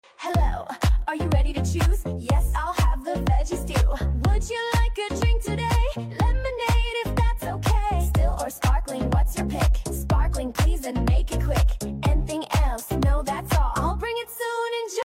This fun and simple English song teaches essential restaurant phrases and polite expressions used in everyday conversations.
🎵 Learn pronunciation, sentence patterns, and real-world English through catchy music.